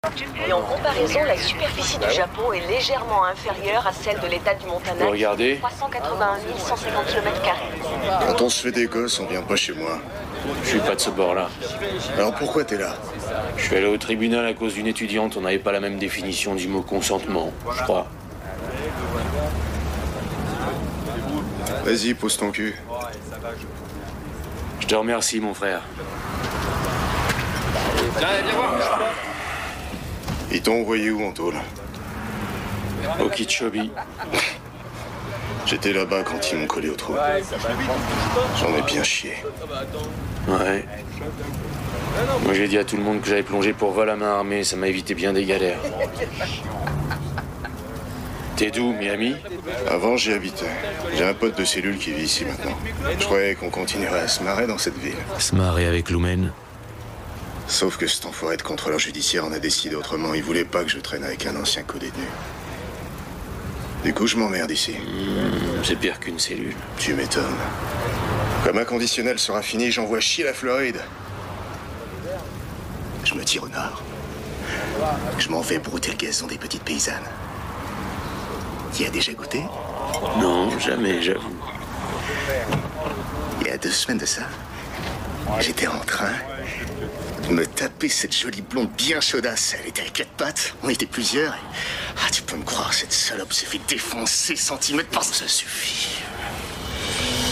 Doublage de Dexter : ma voix méchante, sale, sarcastique et vulgaire pour vos projets
Dans ce rôle, j’ai dû moduler ma voix médium grave pour transmettre la personnalité sale, sarcastique et vulgaire du personnage, tout en restant crédible et touchant.